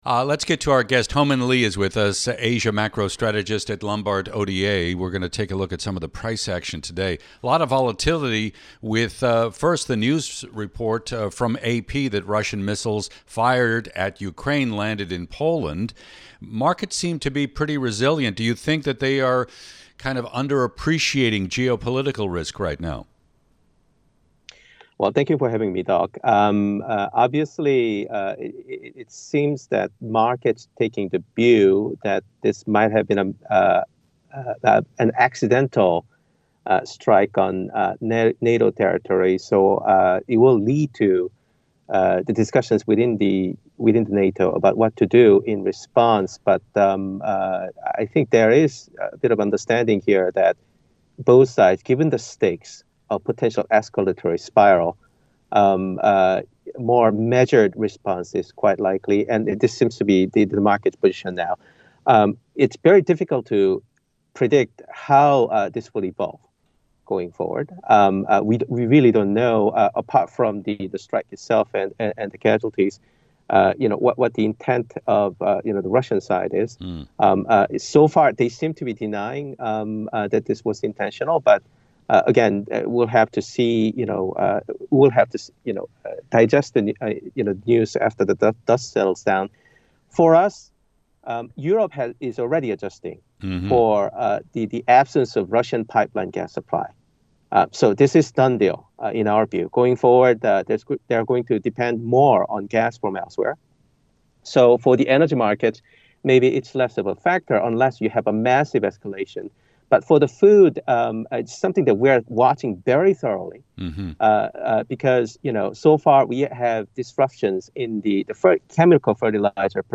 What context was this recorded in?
(Radio) - Bloomberg Daybreak: Asia Edition